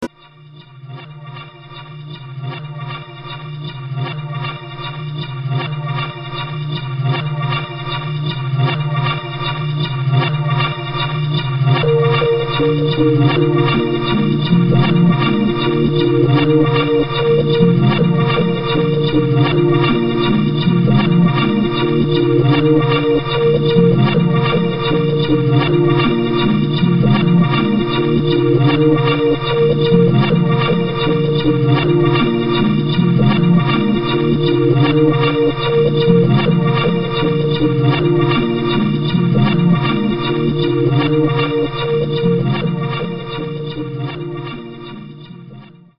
harmonic slow instr.